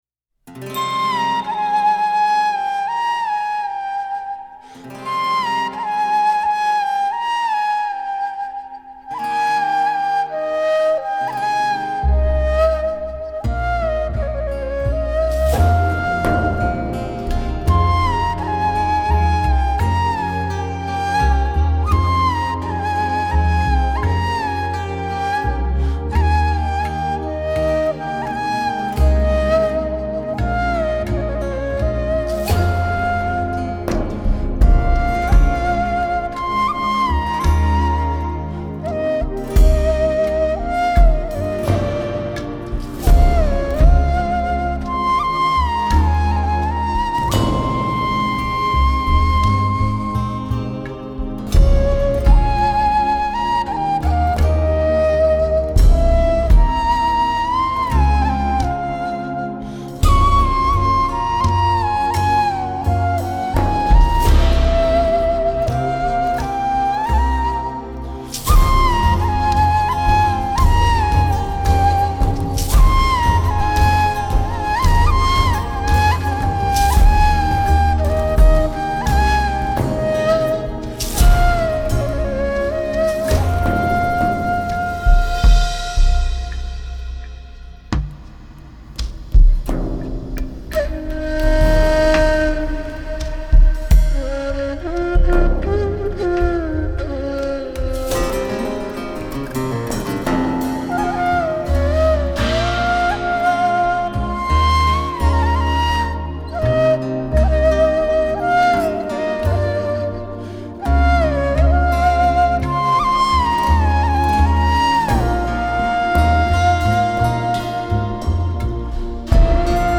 آرامش بخش , غم‌انگیز , ملل , موسیقی بی کلام
موسیقی بی کلام ترکیه ای موسیقی بی کلام نی